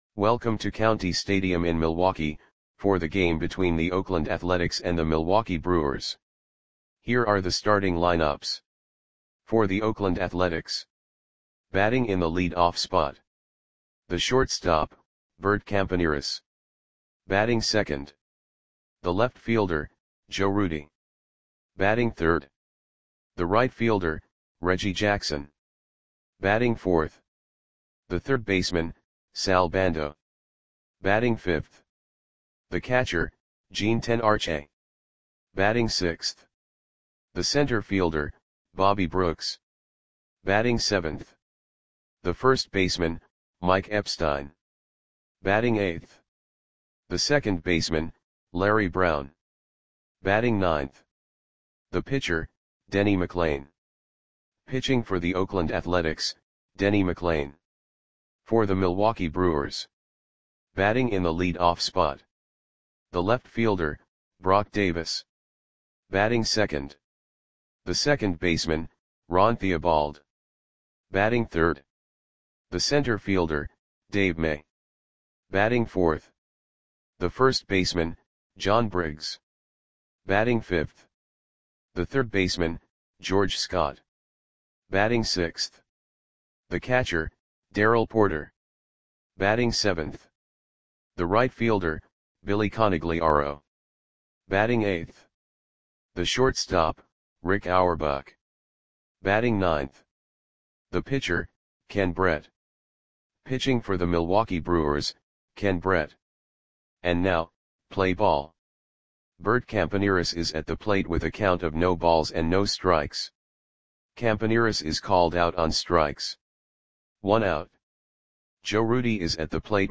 Audio Play-by-Play for Milwaukee Brewers on April 30, 1972
Click the button below to listen to the audio play-by-play.